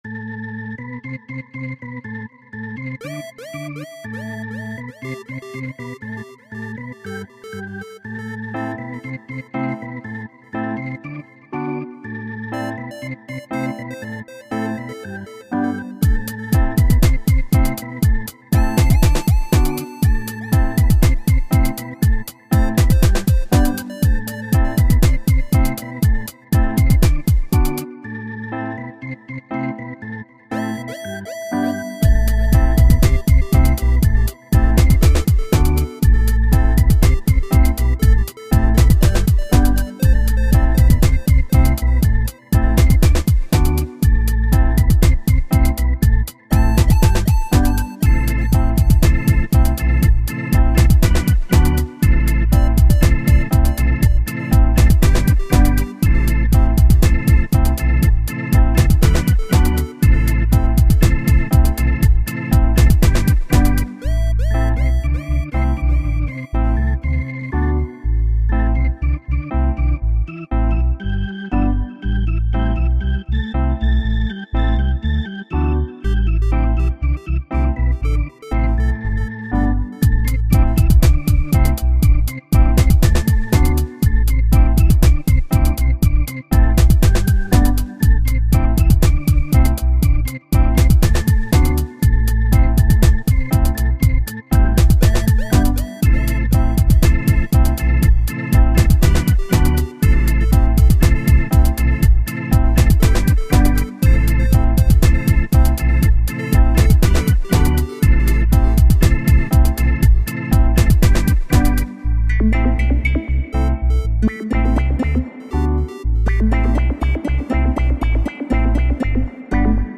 Dub production, made with Reason & Consciousness.